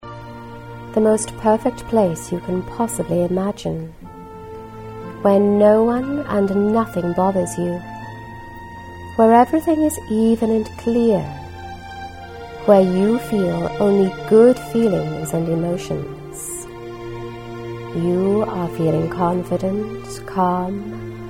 This centring and calming recording is designed for fast complete relaxation.